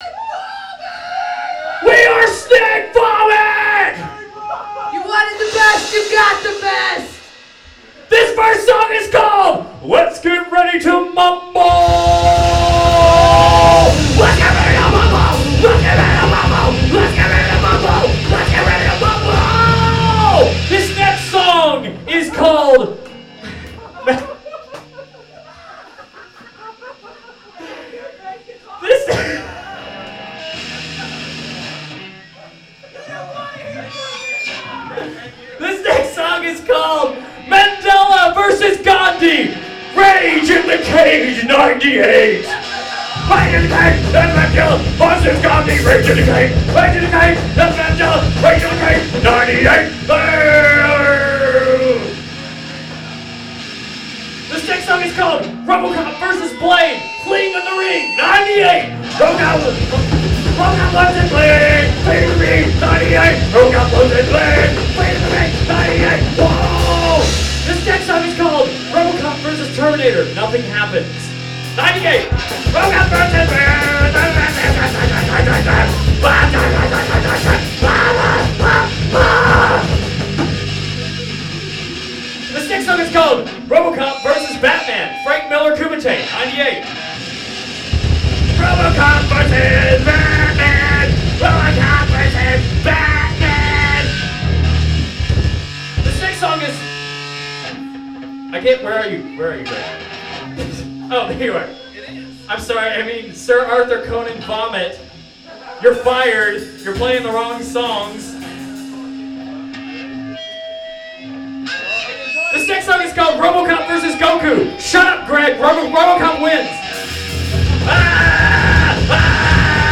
PUNK BAND